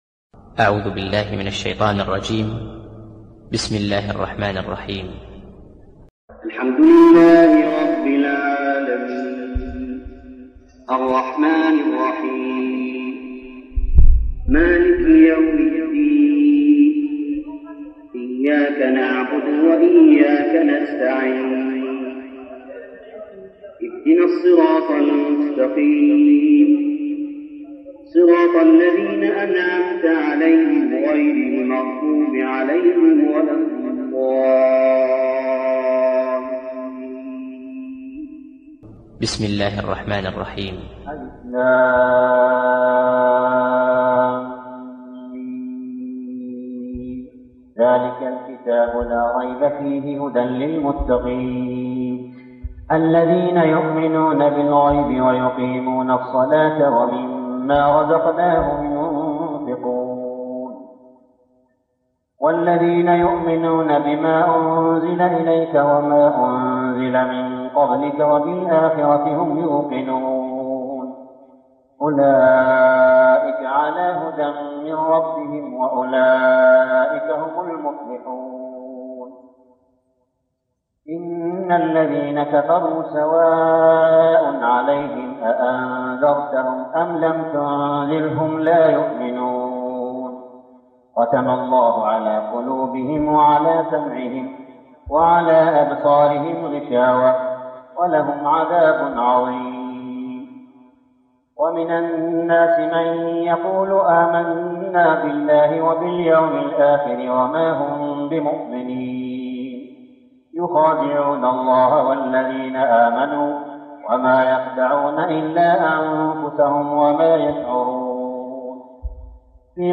صلاة التراويح ليلة 1-9-1411هـ سورتي الفاتحة و البقرة 1-74 | Tarawih prayer night 1-9-1411 AH Surah Al-Fatihah and Al-Baqarah > تراويح الحرم المكي عام 1411 🕋 > التراويح - تلاوات الحرمين